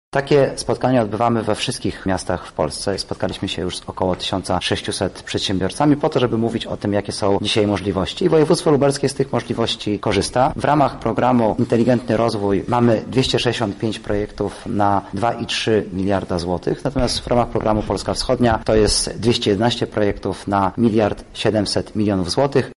O szczegółach mówi wiceminister Inwestycji i Rozwoju Artur Soboń: